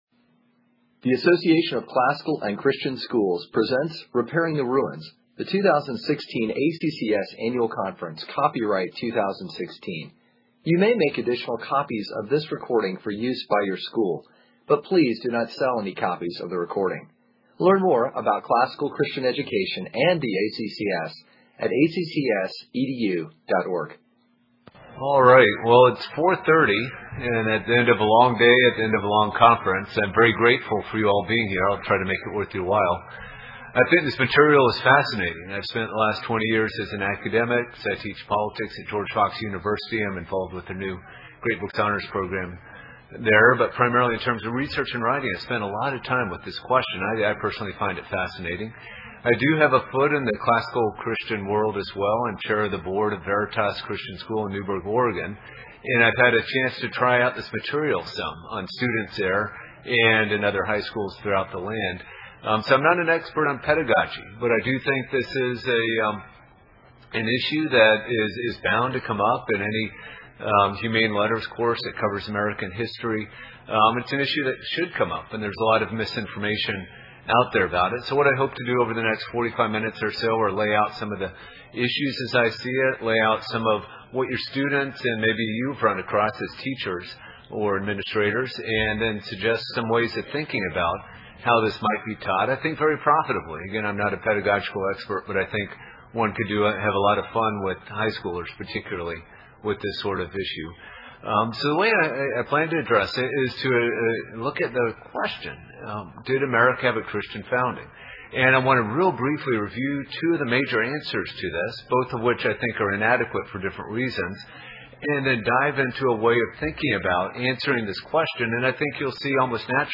2016 Workshop Talk | 1:05:39 | All Grade Levels, History